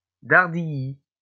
Dardilly (French pronunciation: [daʁdiji]